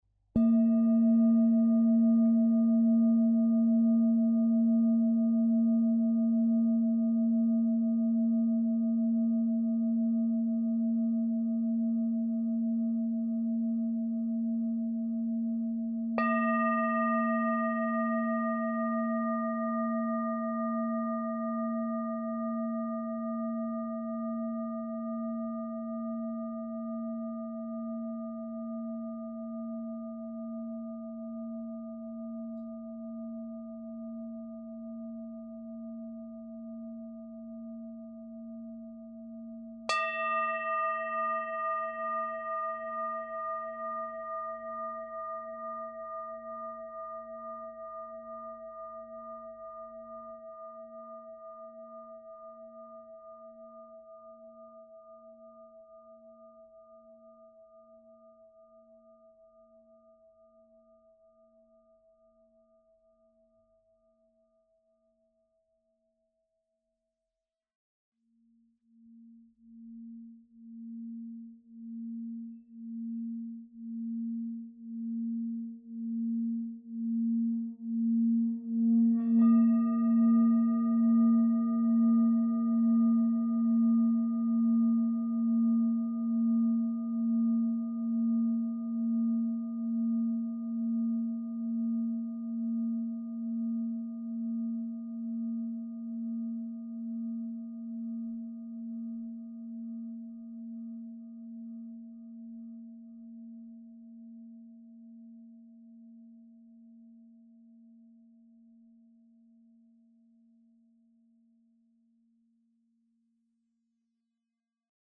Tibetská misa THE BEST SOUND ø 23,5 cm/tón A#
Táto výnimočná spievajúca tibetská misa má vo svojom strede výčnelok, ktorý zvyšuje kvalitu zvuku a významne predlžuje dobu jeho trvania. viac
Táto misa je dovezené z Indie a jej základný tón je A#
Nahrávka tejto Tibetské misy na počúvanie tu
Materiál kov/mosadz